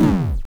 snd_hurt1.wav